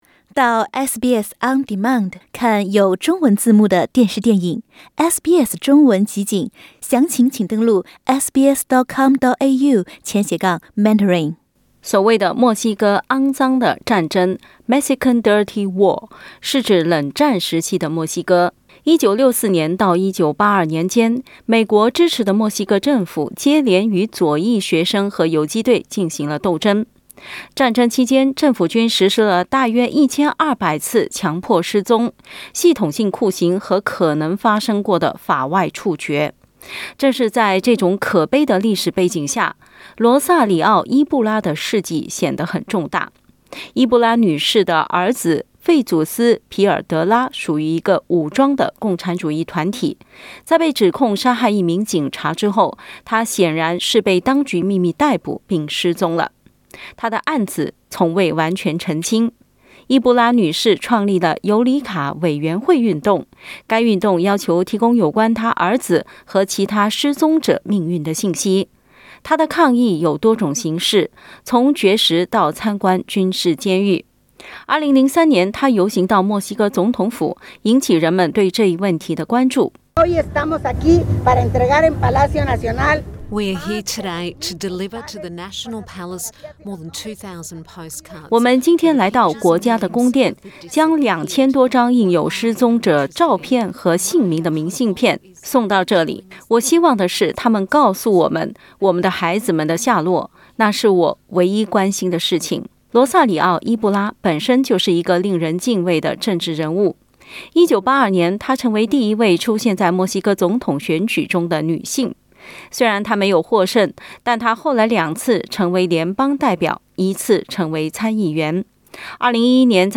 （點擊圖片收聽報道）